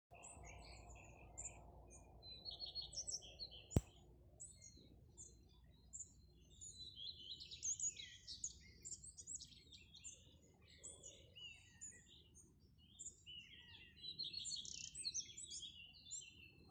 Erickiņš, Phoenicurus phoenicurus
Ziņotāja saglabāts vietas nosaukumsBauskas nov Vecumnieku pag.
StatussDzied ligzdošanai piemērotā biotopā (D)